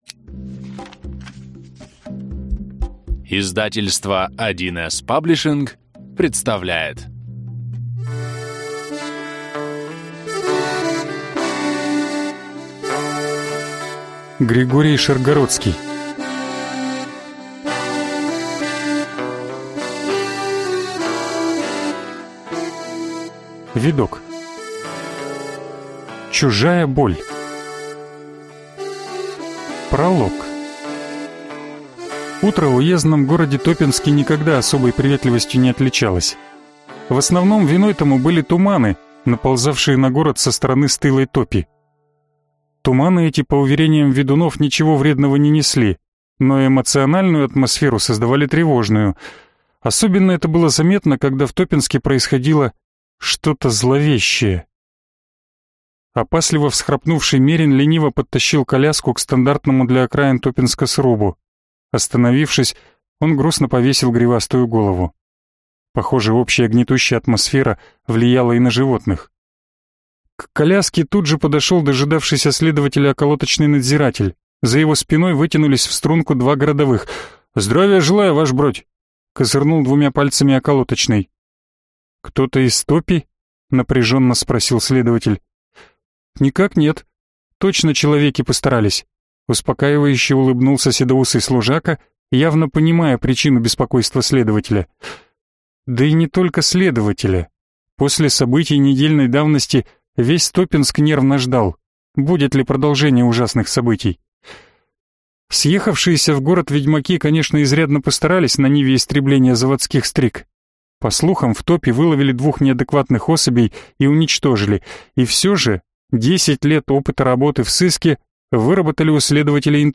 Вы скачиваете фрагмент книги предоставленной издательством